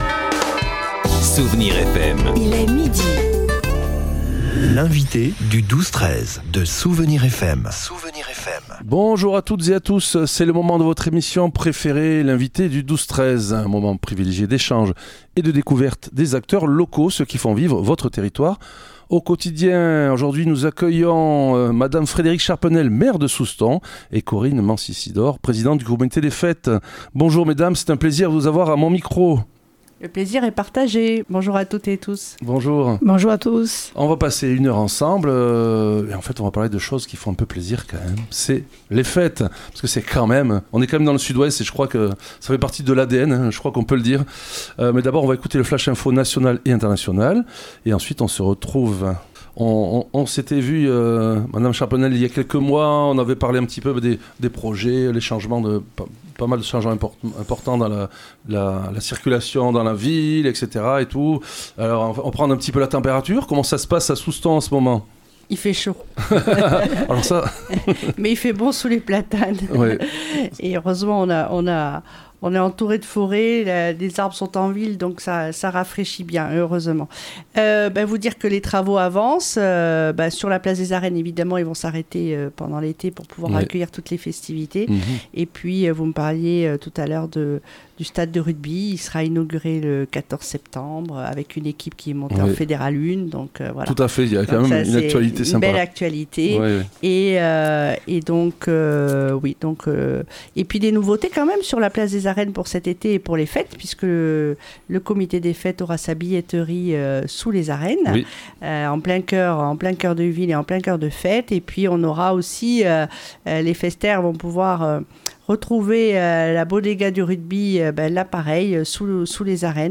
L'invité(e) du 12-13 de Soustons recevait aujourd'hui Madame Frédérique Charpenel, maire de Soustons